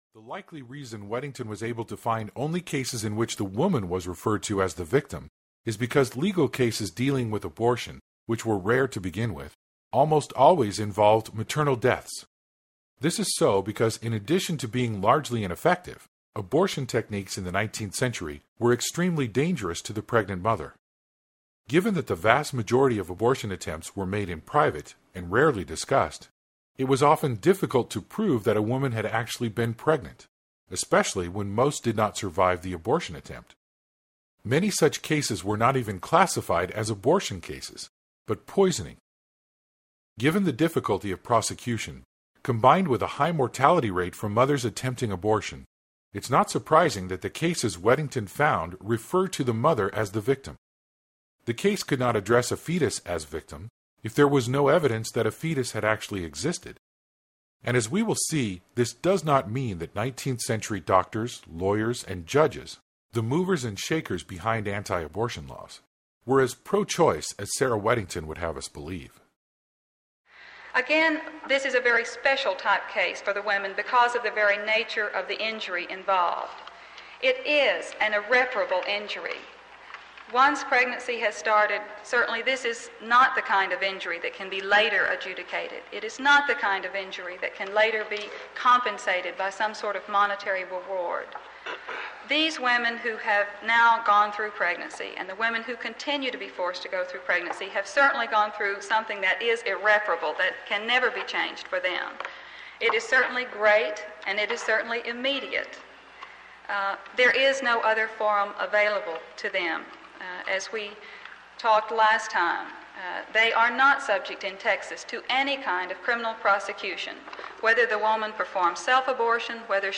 Compelling Interest Audiobook
12.4 Hrs. – Unabridged